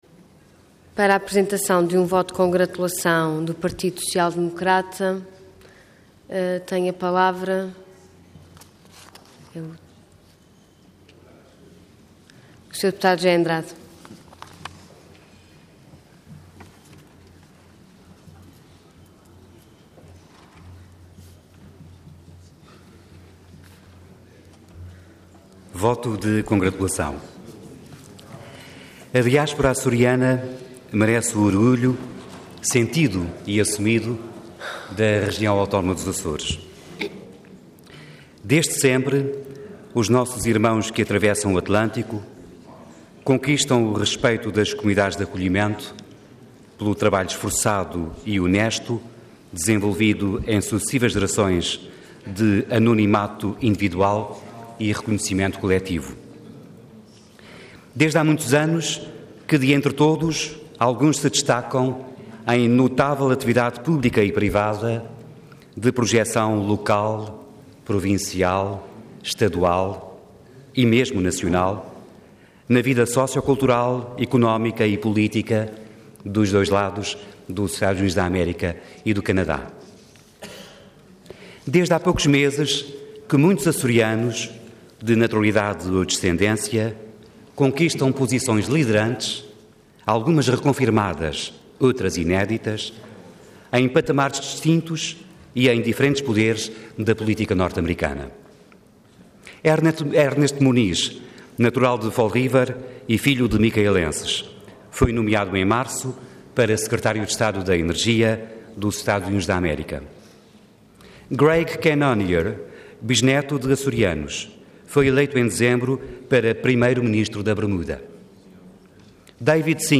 Website da Assembleia Legislativa da Região Autónoma dos Açores
Intervenção Voto de Congratulação Orador José Andrade Cargo Deputado Entidade PSD